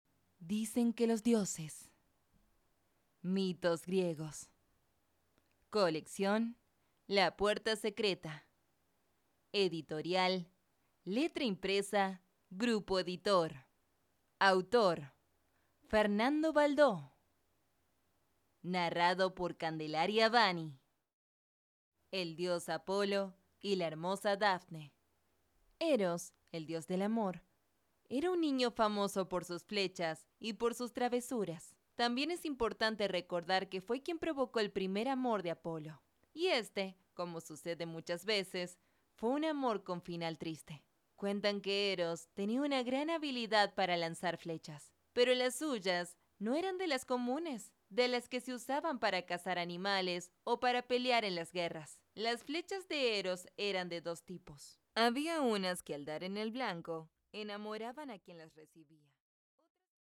Muestra de audiolibro